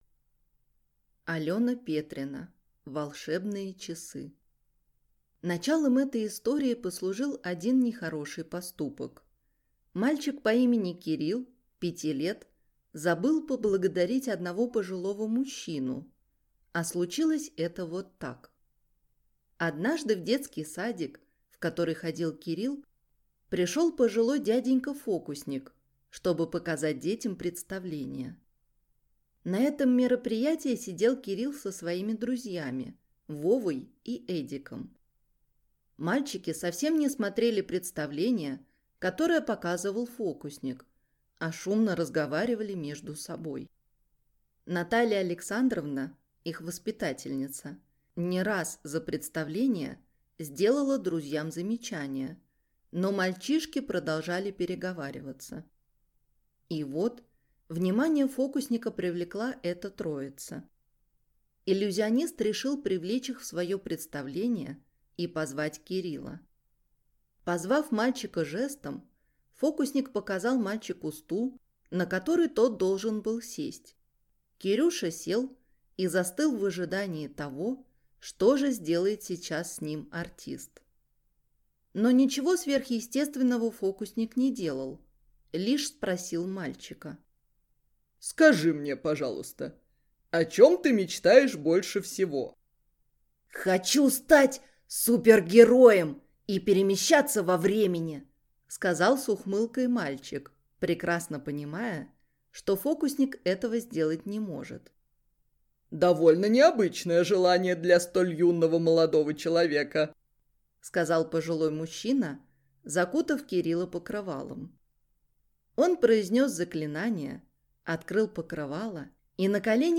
Aудиокнига Волшебные часы Автор Алёна Сергеевна Петрина Читает аудиокнигу